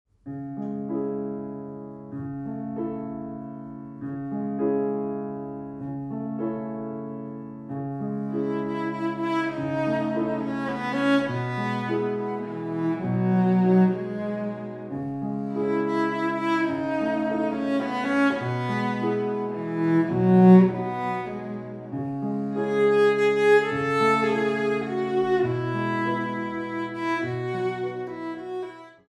Piano music for the dance studio
Port de bras